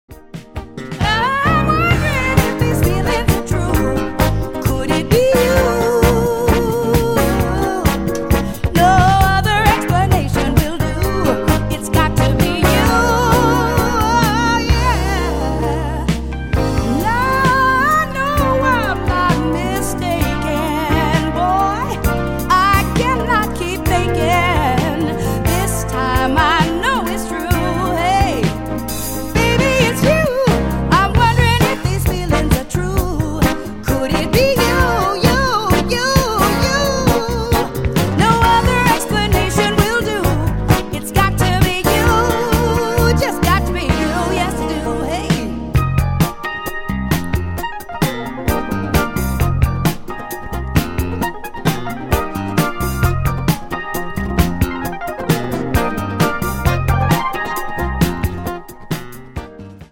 16 Classic songs from one of the great female singers.